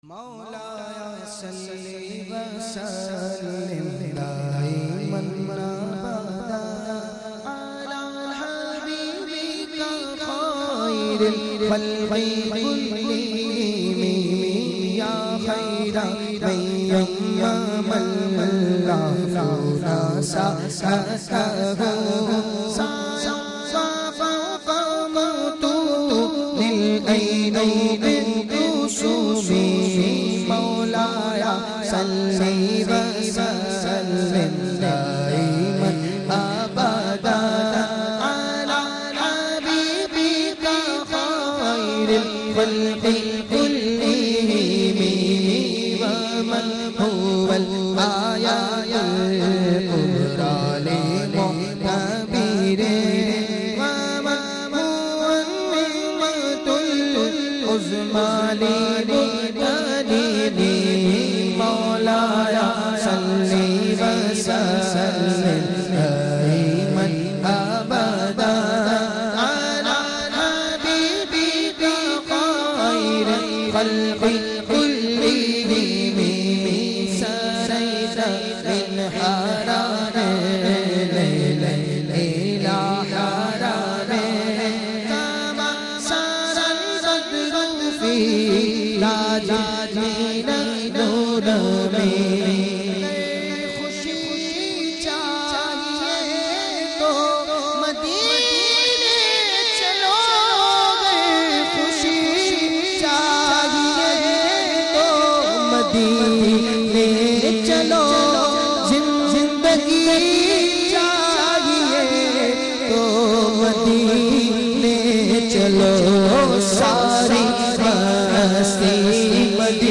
recited